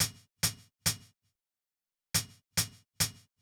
drum_intro.wav